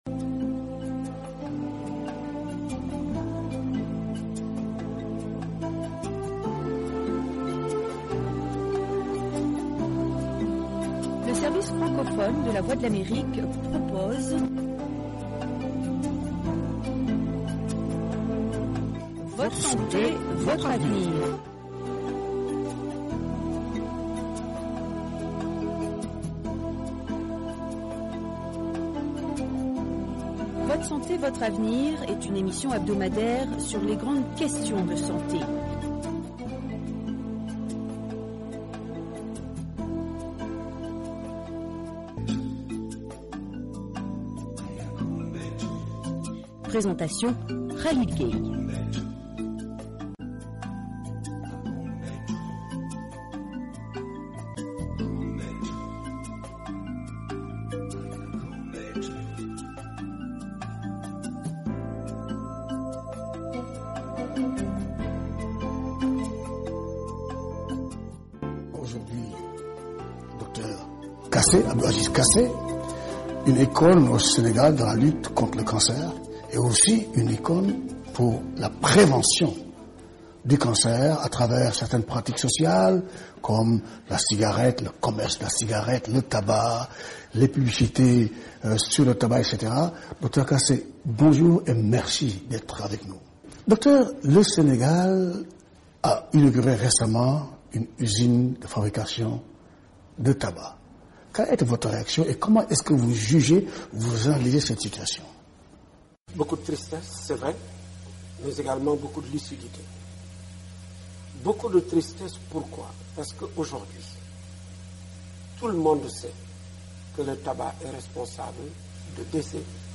L'entretien est axé sur les méthodes, démarches et combats qui ont mené aux différents succès de la lutte contre le tabac au Sénégal.